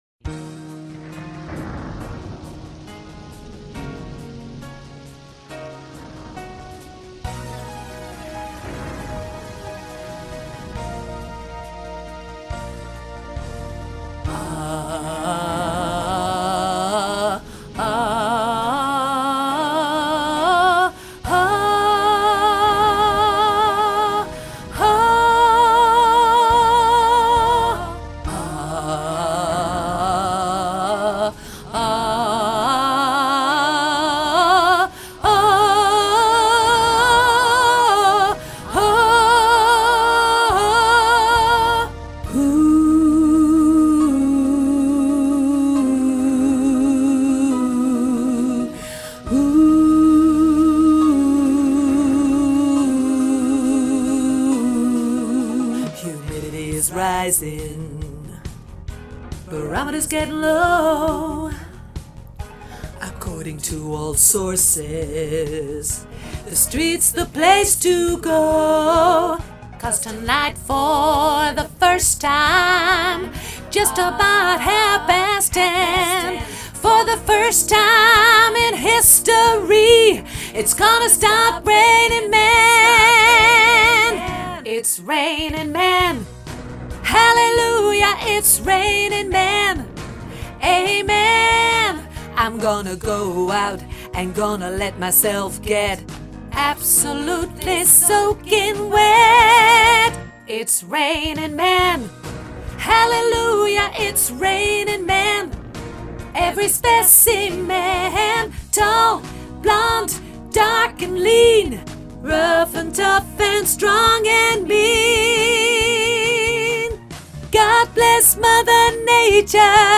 sopraan hoog